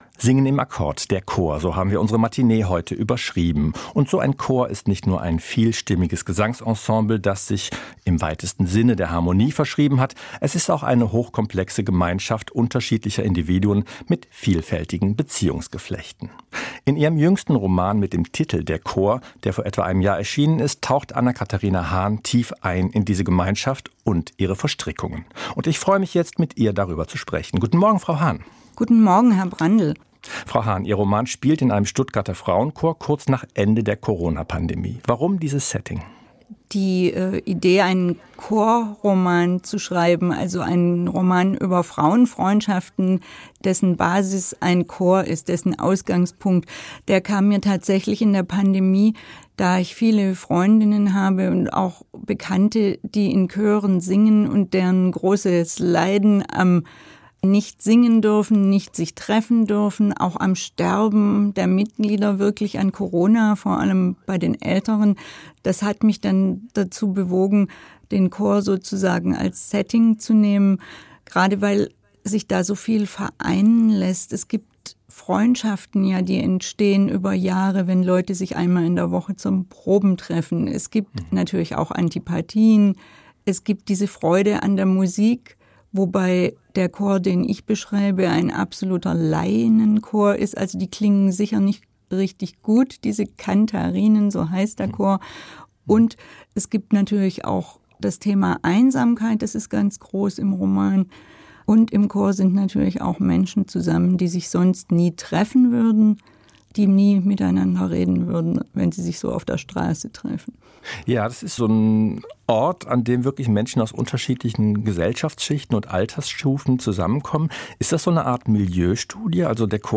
Darüber und über die Playlist zu ihrem Buch spricht sie im Interview.
Das Interview führte